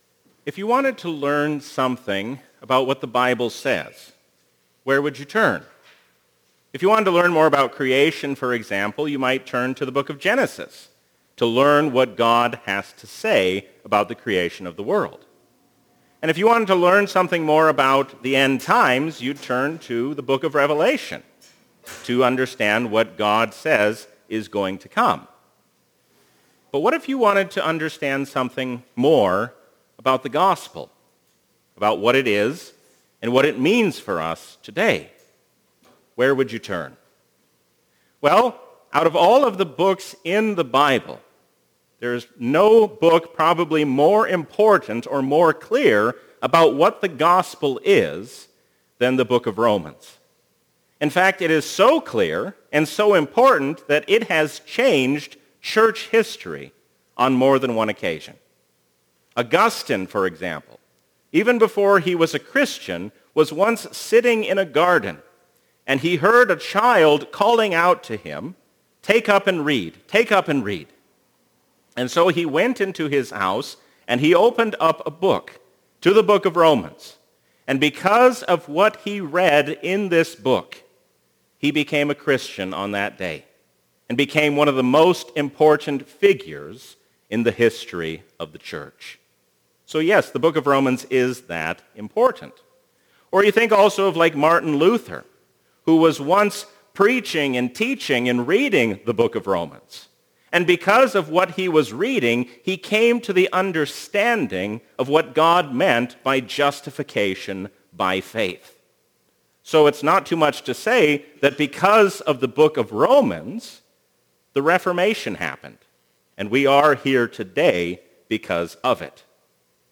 A sermon from the season "Epiphany 2022." Do not lose heart, because God is the Master of all our ways.